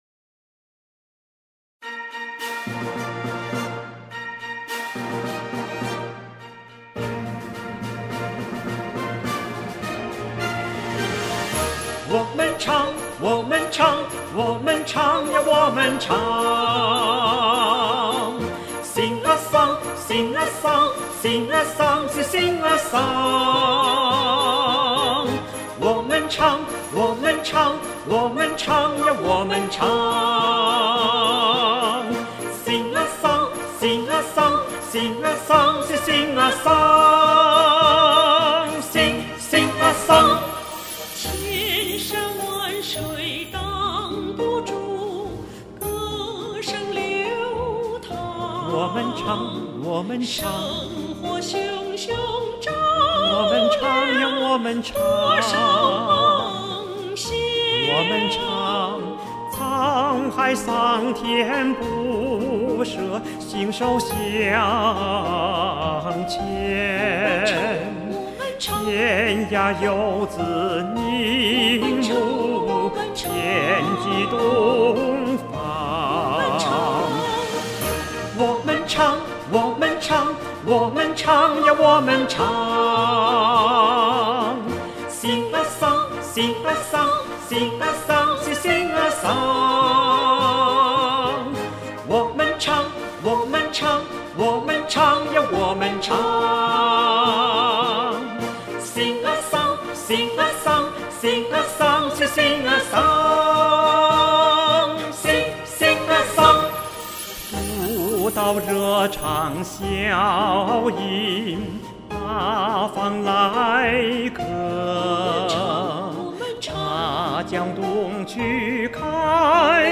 示范演唱。